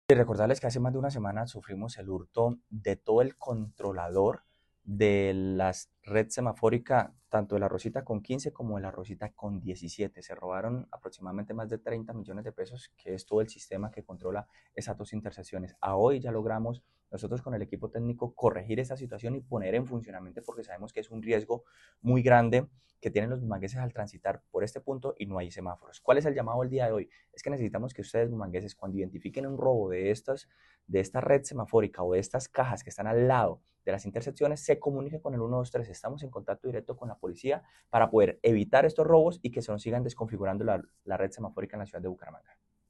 Director de Tránsito de Bucaramanga, Jhair Manrique,